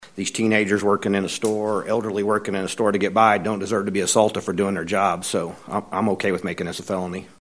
CLICK HERE to listen to details from Representative John George of Newalla.